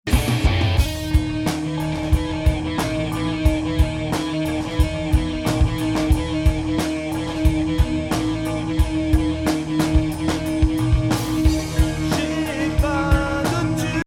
en extrait à écouter voici un petit prémix (sans mastering ni autre finalisation) :